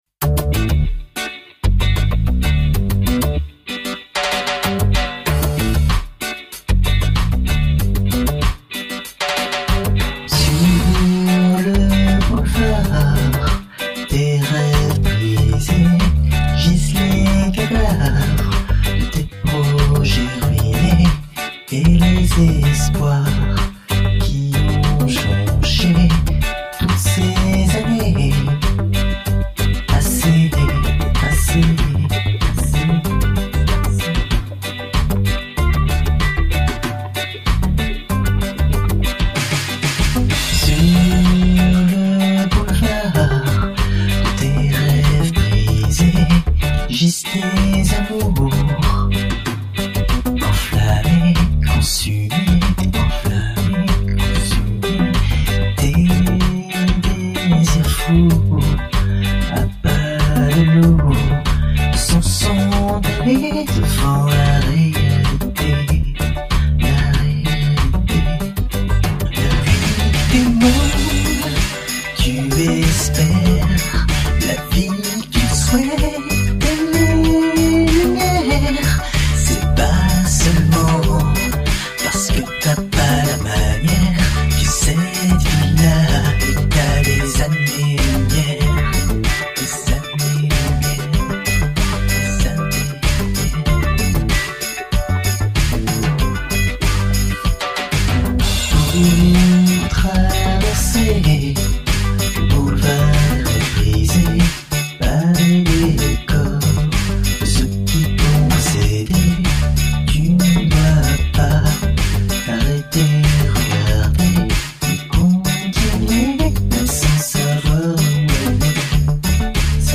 un reggae donc, tout sauf roots
6 cordes
Je pense que l'instru est sympa mais j'aime pas super ta voix, notamment l'effet dessus.
Ta voix est l'instru ne vont pas super ensemble.
plus haute et à priori pas adapté au reggae et ma musique que je trouvais interessant